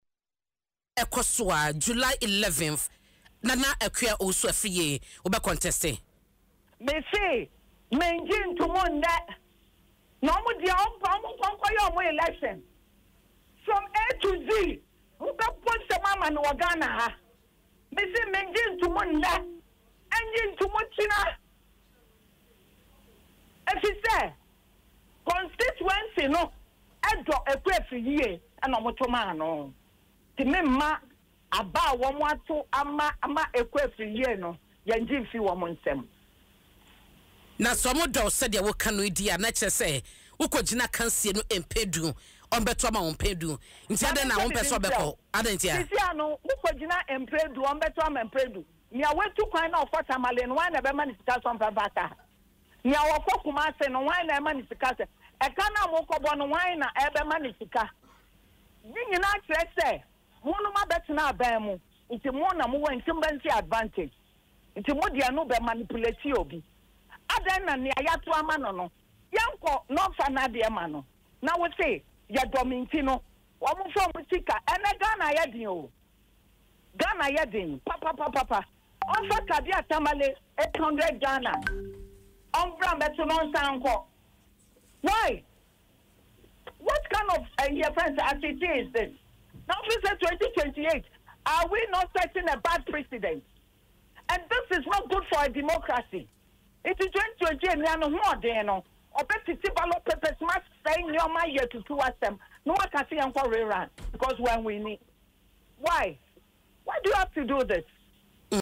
Speaking on Adom FM’s Dwaso Nsem, the former MP insisted that she won the 2024 parliamentary election “fair and square,” citing results from all pink sheets in her possession.